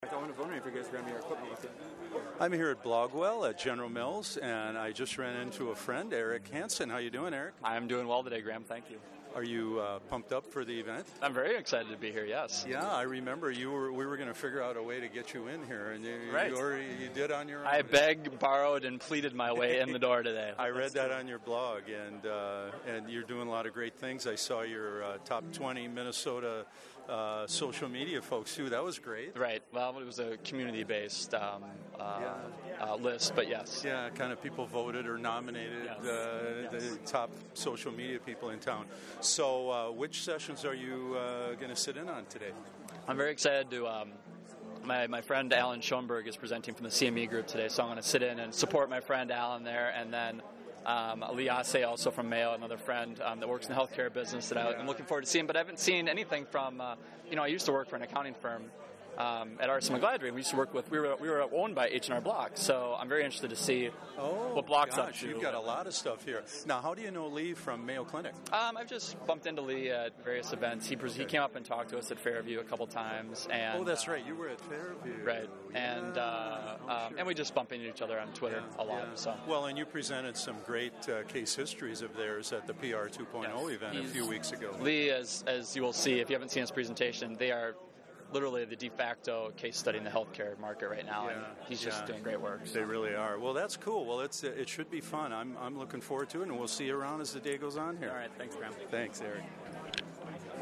My Interviews: I took along my trusty studio-grade handheld recorder (the Olympus LS-10) and grabbed seven brief audio interviews before and after the sessions, and during breaks.